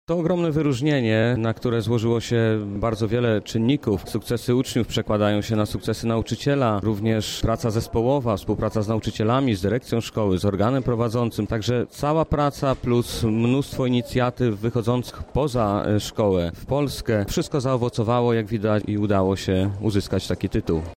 Na wojewódzkich obchodach Dnia Edukacji Narodowej w Lubelskim Parku Naukowo Technologicznym zostały wręczone odznaczenia państwowe dla ponad 200 pedagogów.